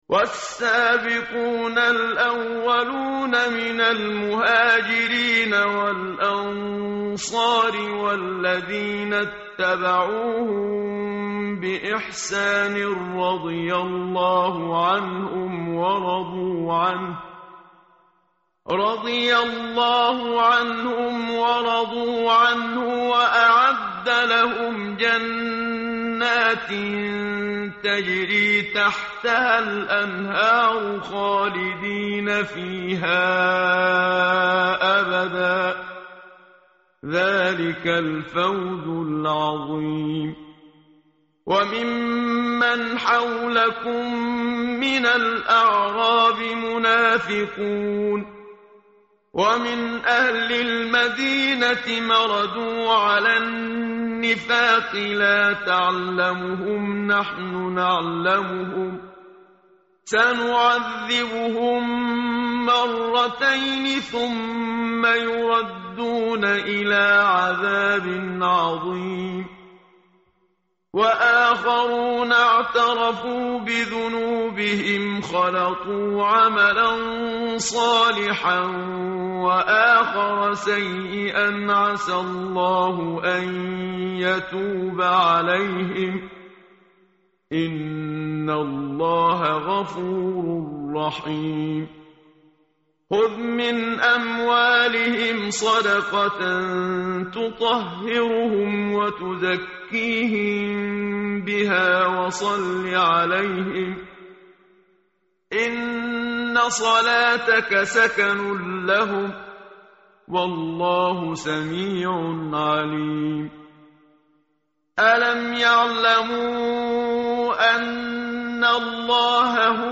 tartil_menshavi_page_203.mp3